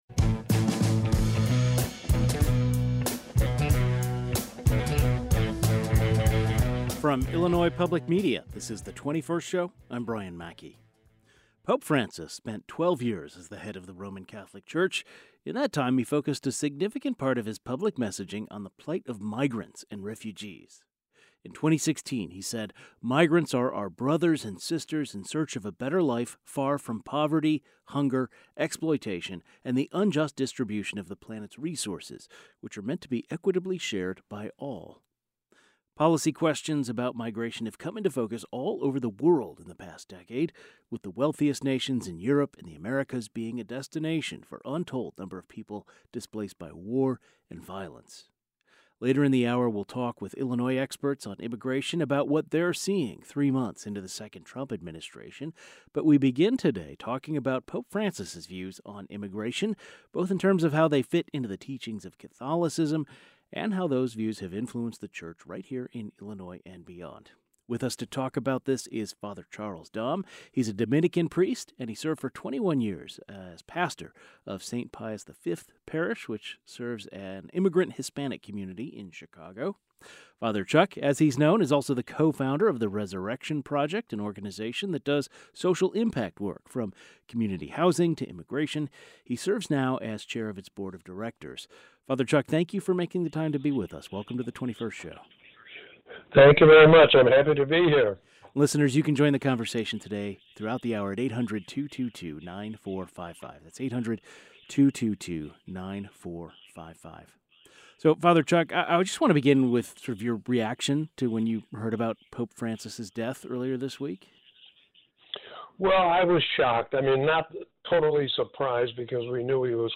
A priest who works with immigrant communities in Chicago discusseshow Pope Francis' advocated for immigrants, migrants, and refugees and how his views fit into the teachings of Catholicism.